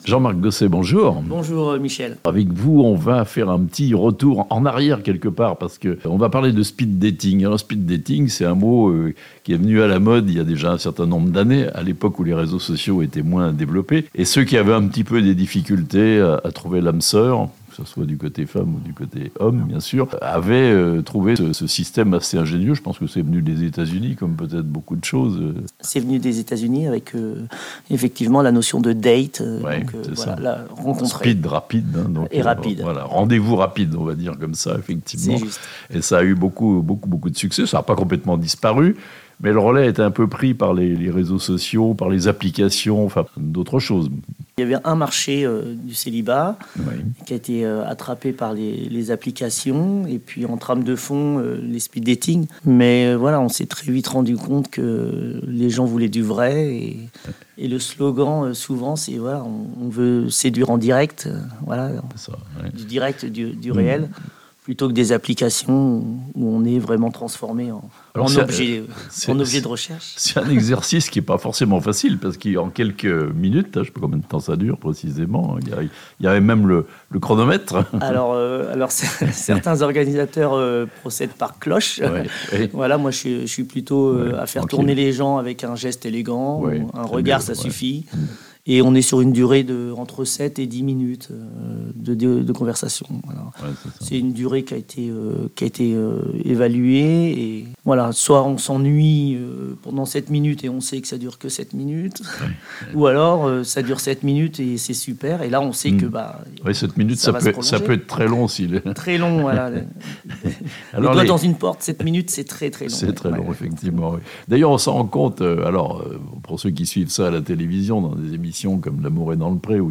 Le retour du « speed dating » dans les Pays de Savoie et en Suisse romande (interview)